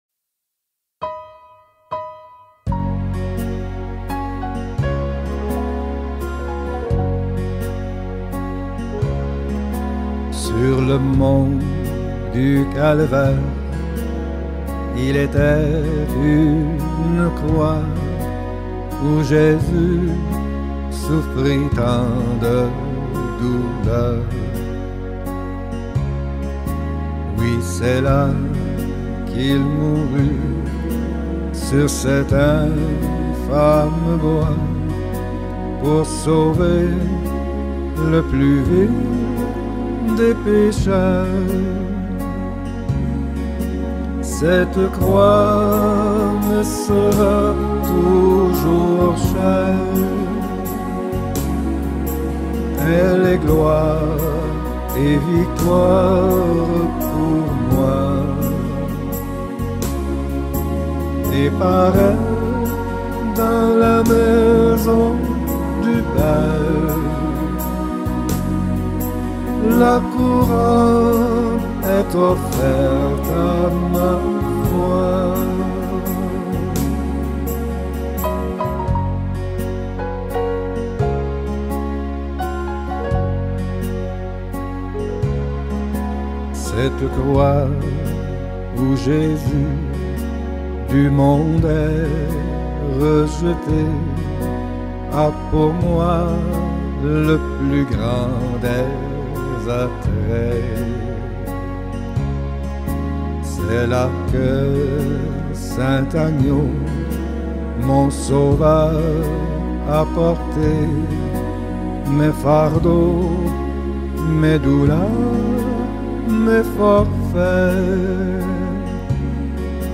50 просмотров 95 прослушиваний 3 скачивания BPM: 80